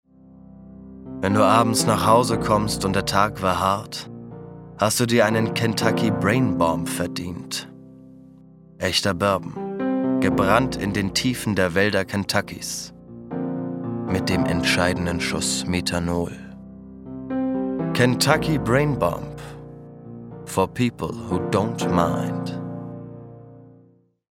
warme Stimme, runde Stimme, Emotional
Sprechprobe: Werbung (Muttersprache):
war voice, round voice, emotional